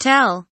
tell kelimesinin anlamı, resimli anlatımı ve sesli okunuşu